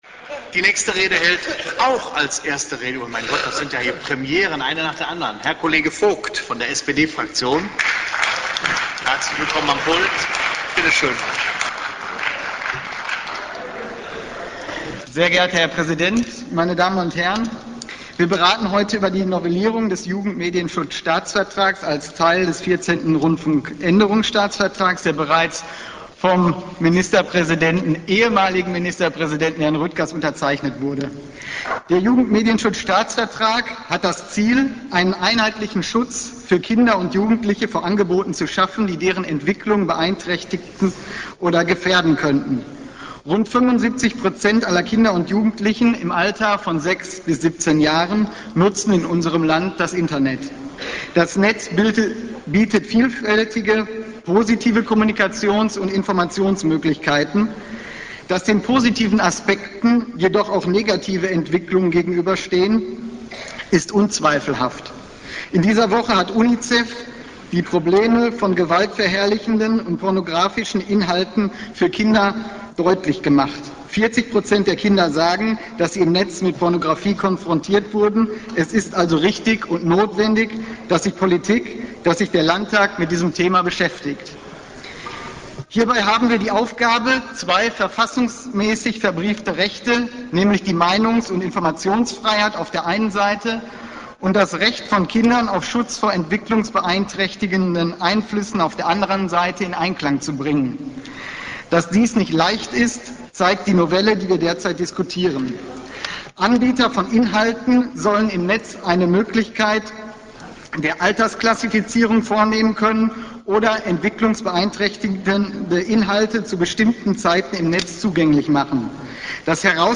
Falls jemand noch einmal reinhören möchte, ich war so frei, die Redebeiträge aus dem aufgezeichneten Real-Media-Stream zu befreien (Sorry für die lausige Qualität):
Redebeitrag Alexander Vogt (SPD)